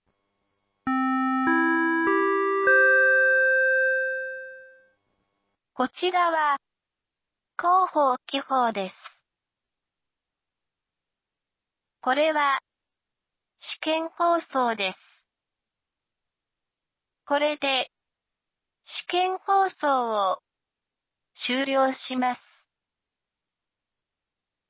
紀宝町防災無線情報